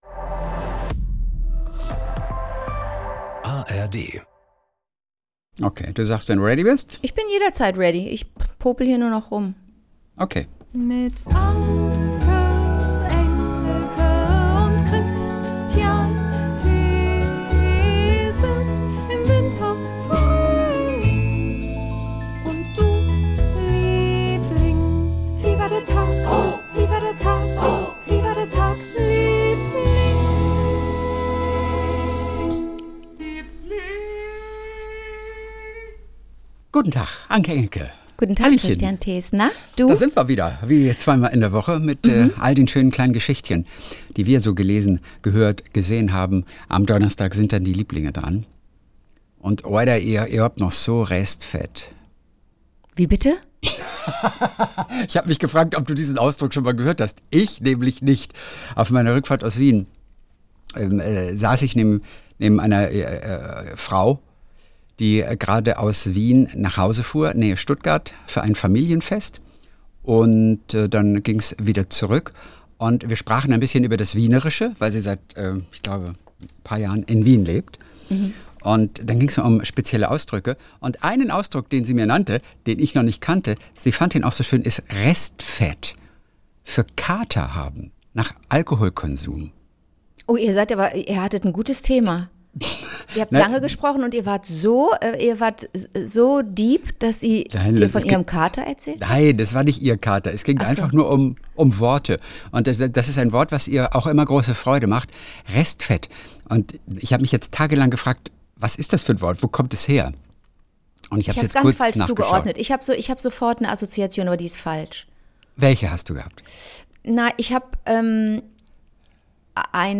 Play episode August 17 1h 7m Bookmarks View Transcript Episode Description Jeden Montag und Donnerstag Kult: SWR3-Moderator Kristian Thees und seine beste Freundin Anke Engelke beiden erzählen sich gegenseitig ihre kleinen Geschichtchen des Tages.